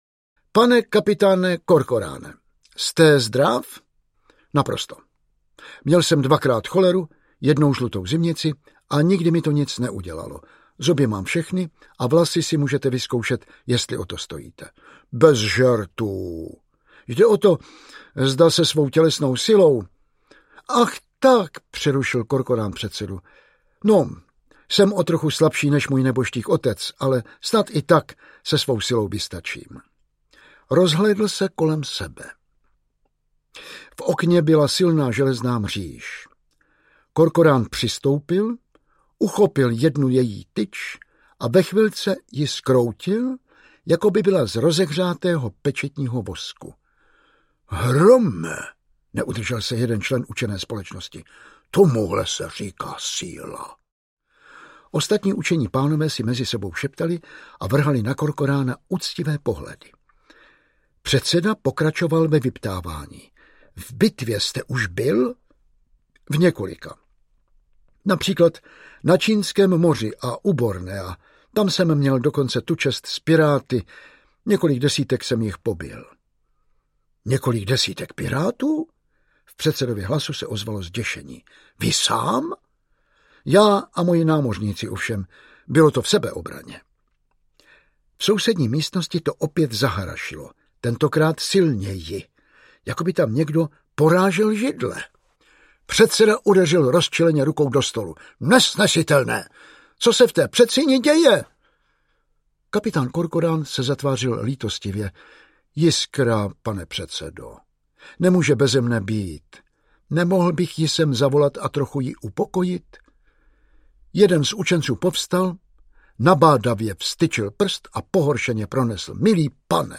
Hrdinný kapitán Korkorán audiokniha
Ukázka z knihy
Čte Jan Vlasák.
Vyrobilo studio Soundguru.